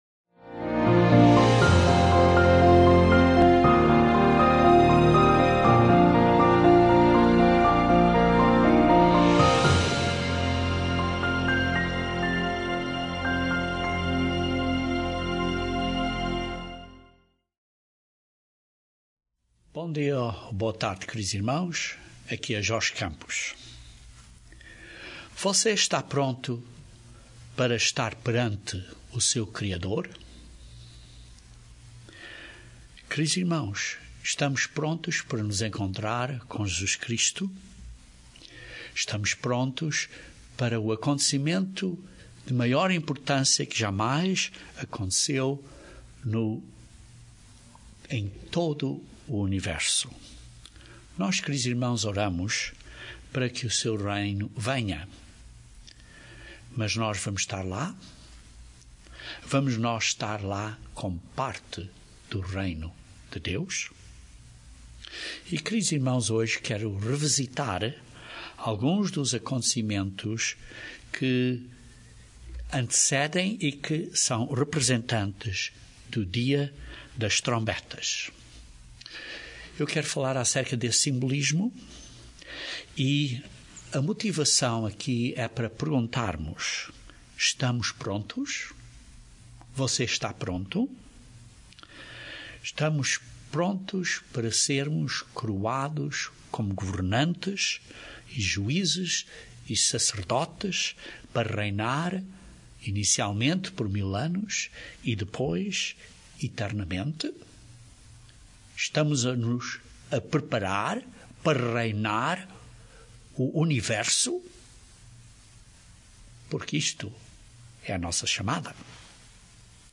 Este sermão descreve estes detalhes numa maneira simples de entender.